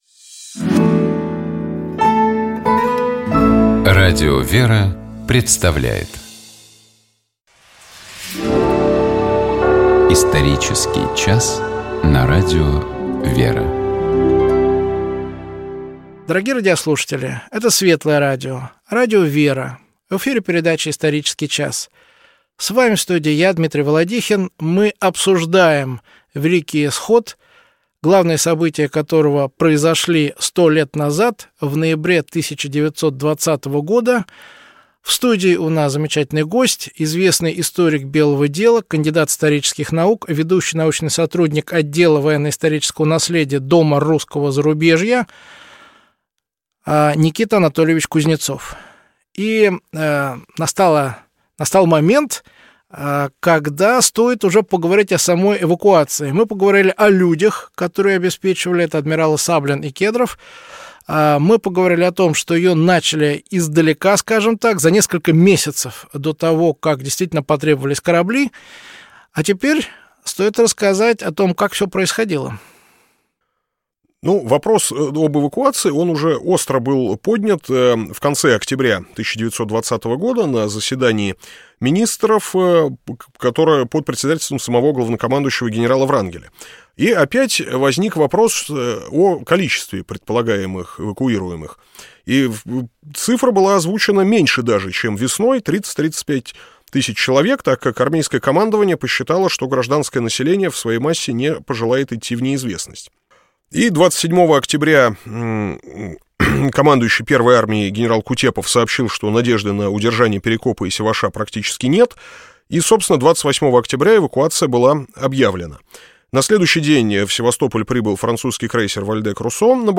Мы говорили об особенностях и значении эвакуации Белых сил с Крымского полуострова в ноябре 1920 года. Разговор шел о том, как готовилась эвакуация, и какие люди обеспечивали её. Наши гости размышляли над тем, какие духовные причины могли привести к таким трагическим событиям 20 века.